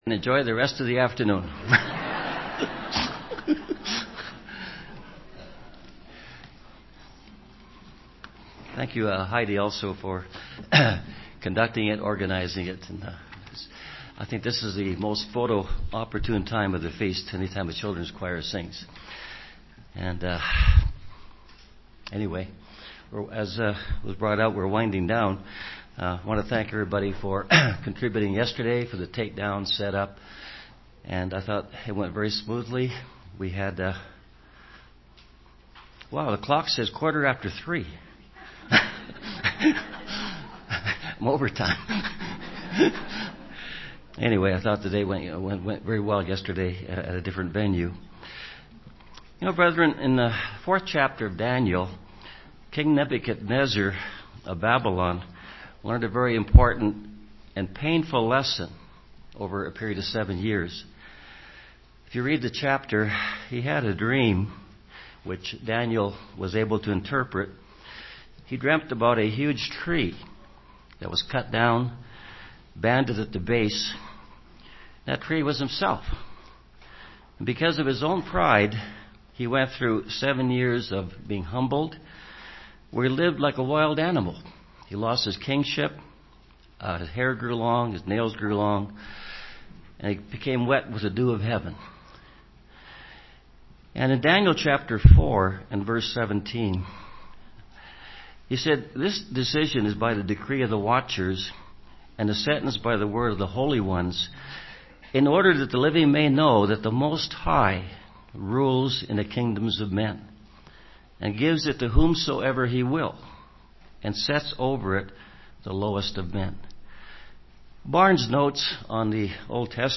This sermon was given at the Canmore, Alberta 2015 Feast site.